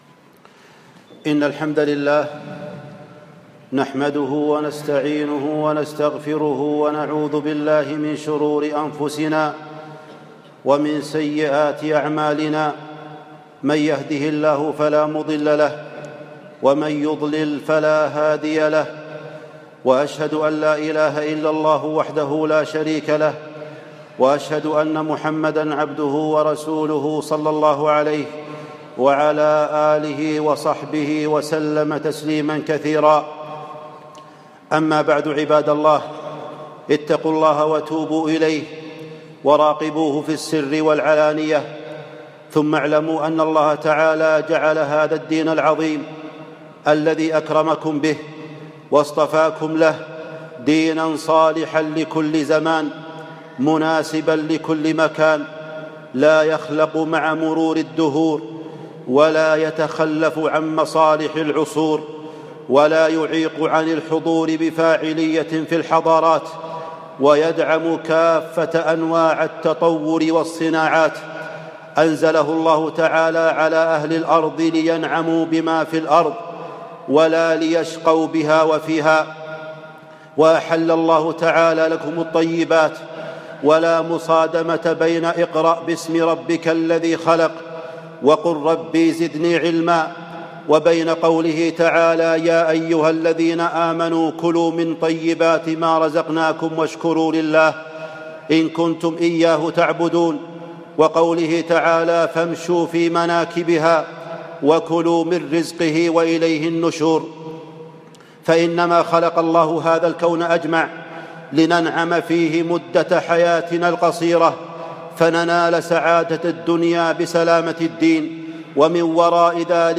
مسجد السيل الكبير يوم الجمعة ٧ جمادى الآخرة ١ ٤ ٣ ٩